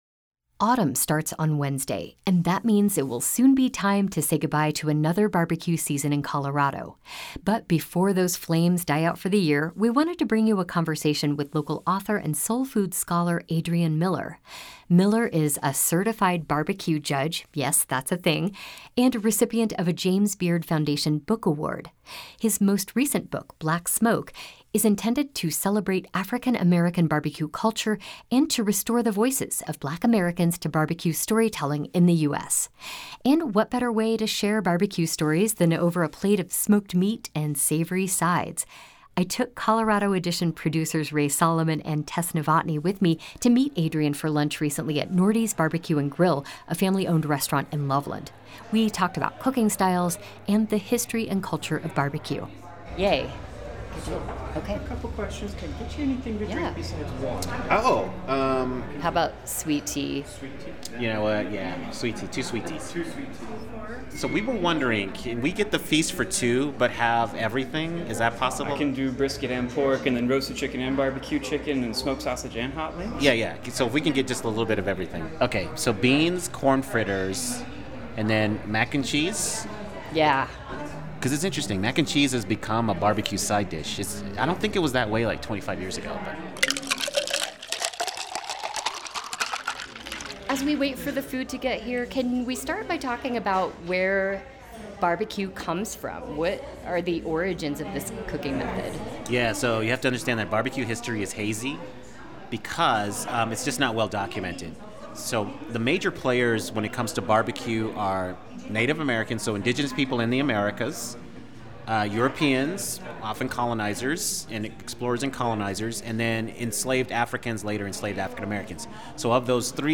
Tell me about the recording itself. for lunch at Nordy's Barbecue and Grill in Loveland. Over a platter of ribs, hush puppies, cornbread and more, they discussed the origins of barbeque, and the stories of Black barbecuers who shaped the culture of barbecue in America.